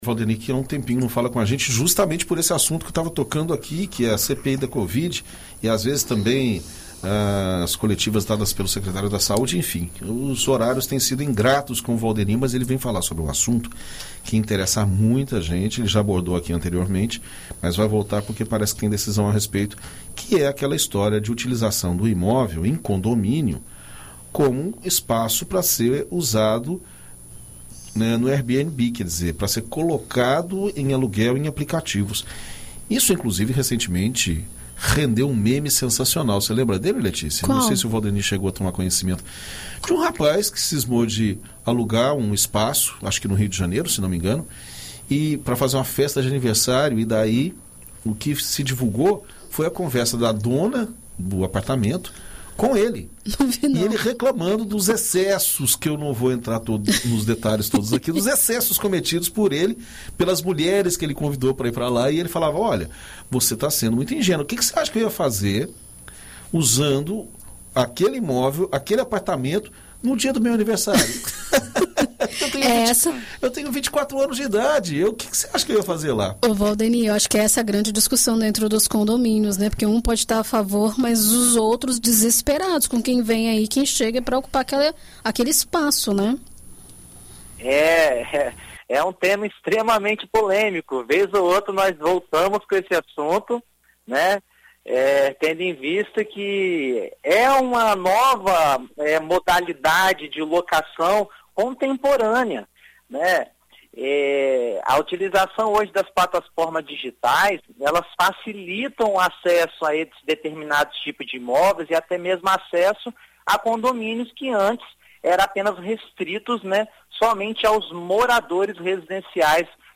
BandNews FM